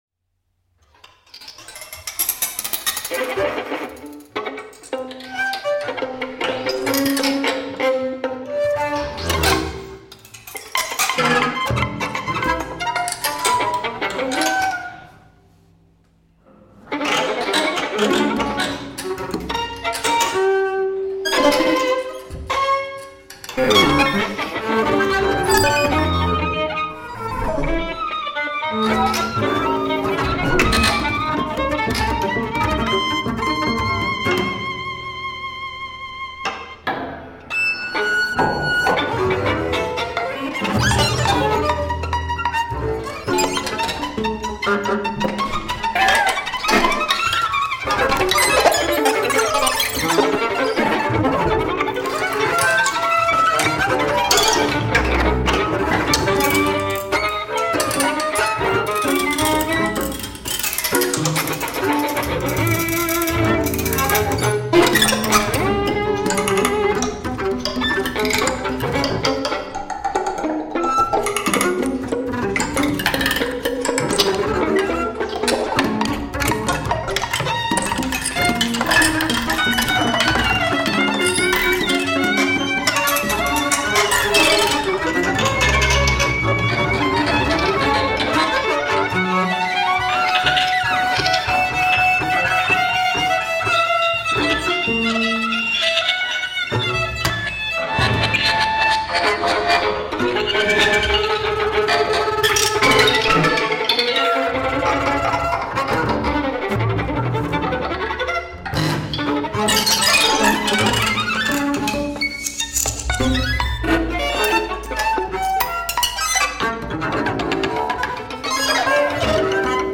improvised music